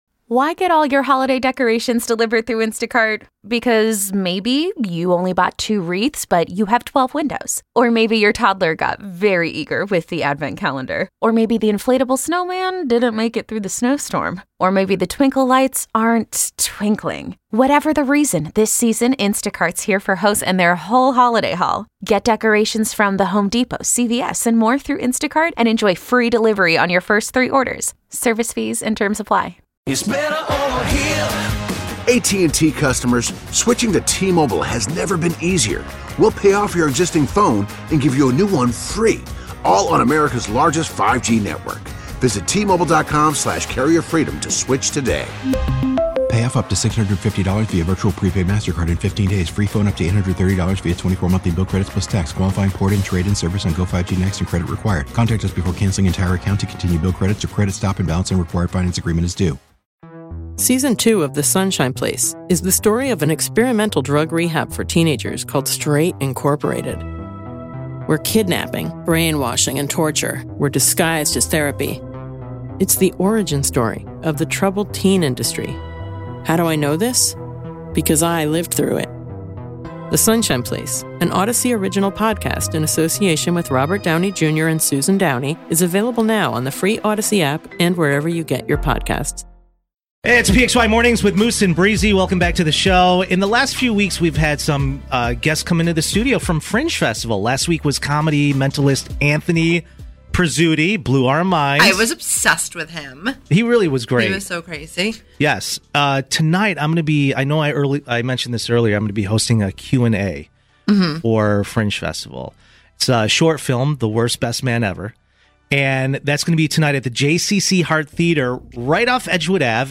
Live in the Studio with us!